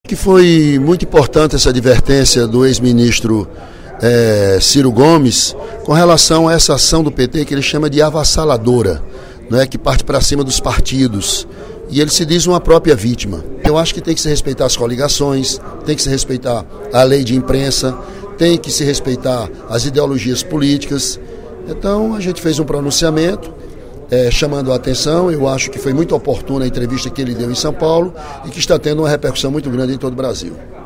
O deputado Ely Aguiar (PSDC) disse, durante pronunciamento na sessão plenária desta quinta-feira (05/07), que a ideologia política no Brasil e no Ceará não existe, só a “ideologia de interesses”.